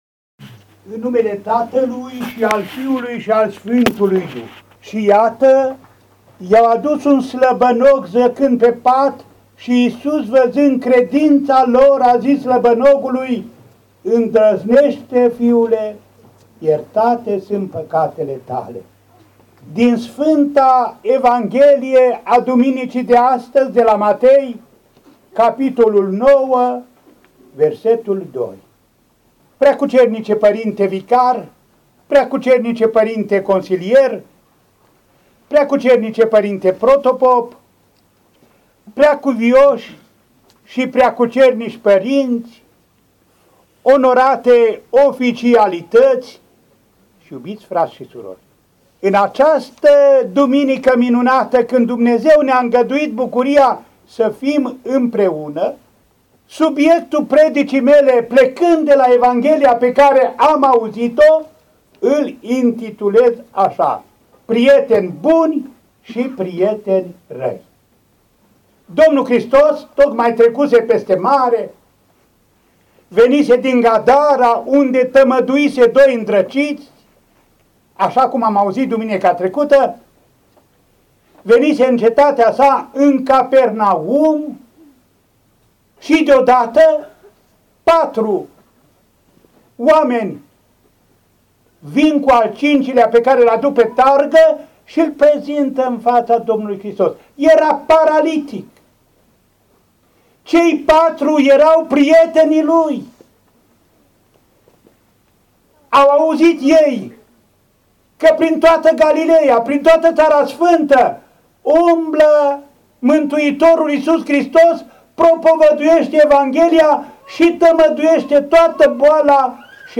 Predică-rostită-de-Înaltpreasfințitul-Andrei-în-Parohia-Molișet.mp3